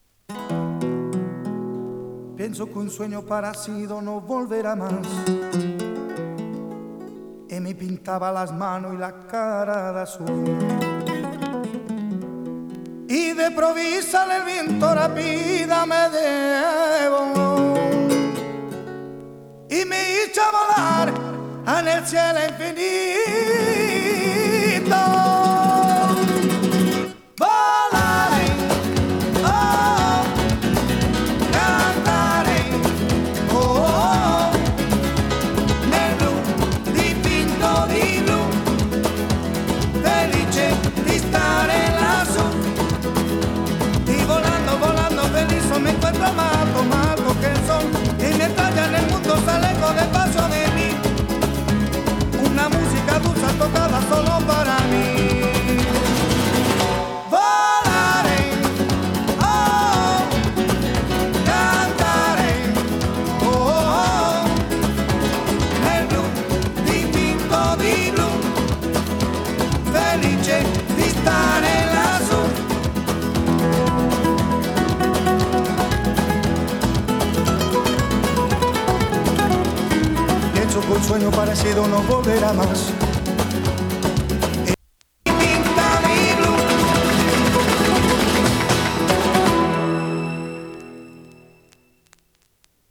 ビールのCMでもお馴染みのラテンポップ人気曲！南仏出身のスペイン系メンバーによるグループ。
[3track 12inch]＊音の薄い部分に所々チリパチ・ノイズ。